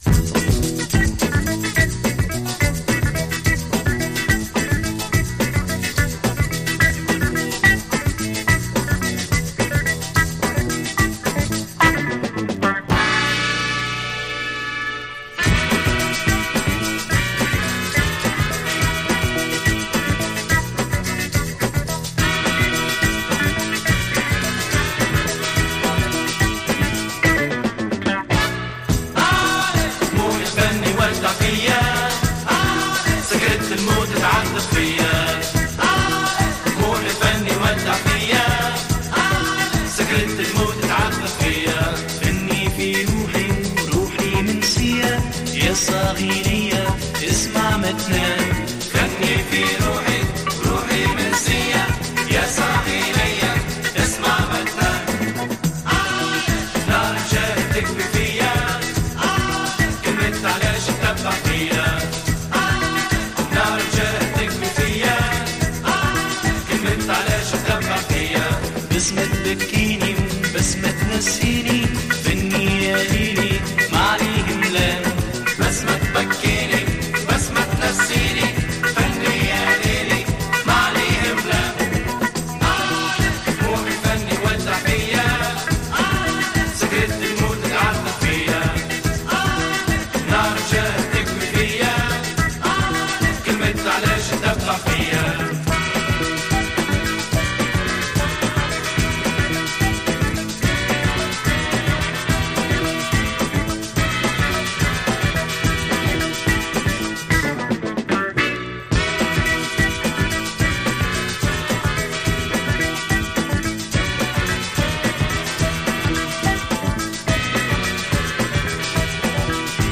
Guitar, Piano, Vocals
Trumpet, Percussion, Vocals
Bass Guitar
Drums, Vocals
Saxophone, Percussion